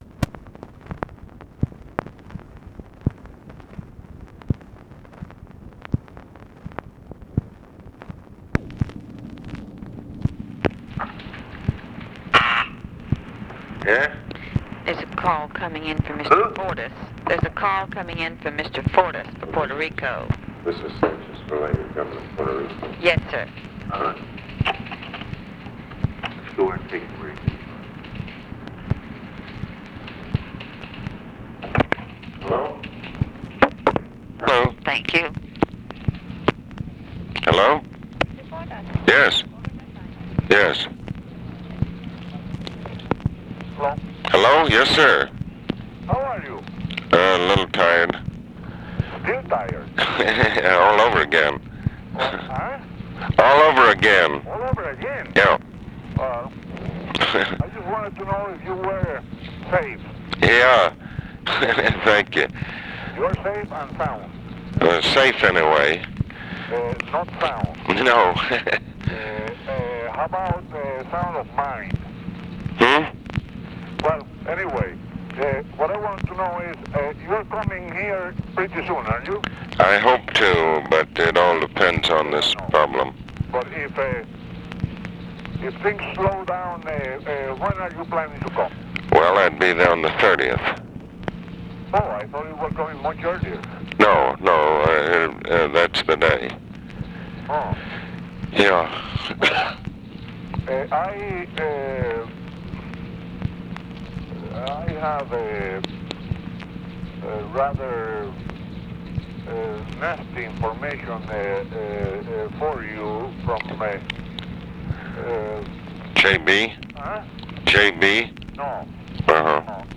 Conversation with ROBERTO SANCHEZ-VILELLA, ABE FORTAS and OFFICE CONVERSATION, May 18, 1965
Secret White House Tapes